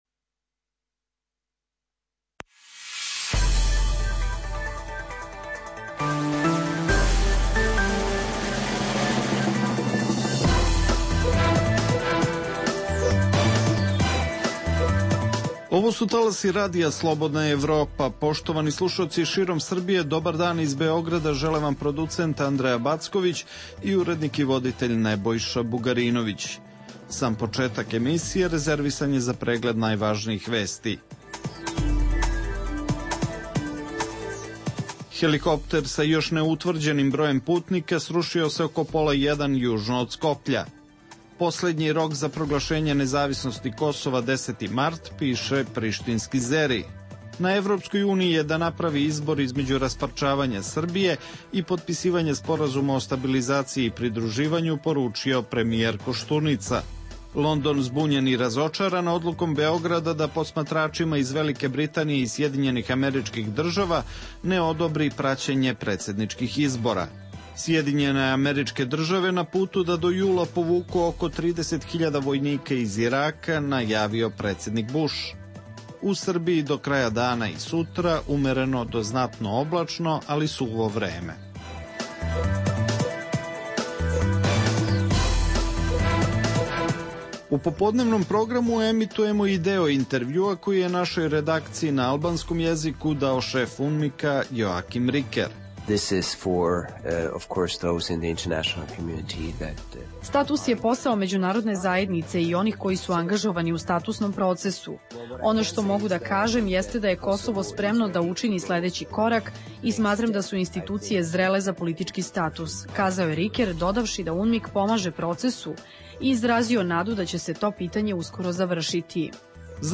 U programu emitujemo izvode iz intervjua koji je šef UNMIK-a dao Radiju Slobodna Evropa. Biće reči i o nameri Vlade Srbije da tokom 2008. godine u infrastrukturne projekte na Kosovu uloži mnogo više sredstava nego do sada, a u drugom delu emisije emitujemo našu redovnu vikend rubriku Gaf nedelje.